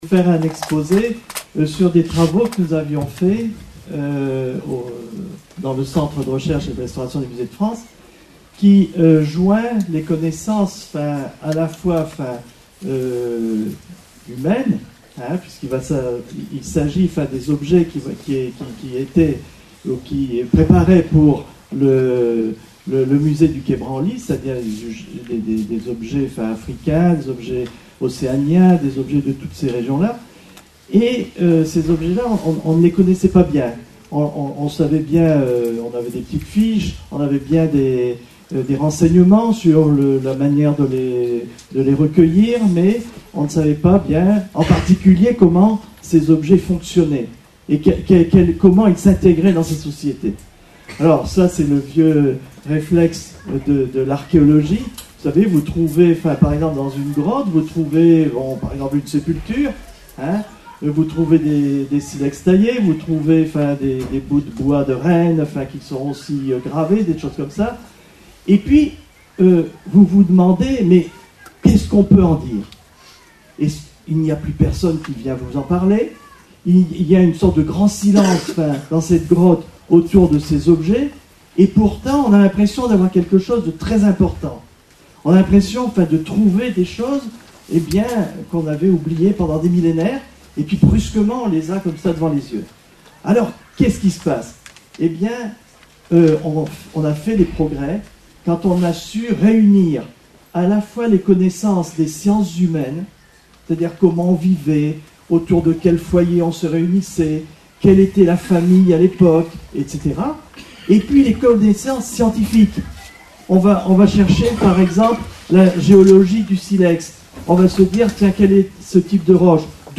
Lycée Gaston Febus (64 Orthez)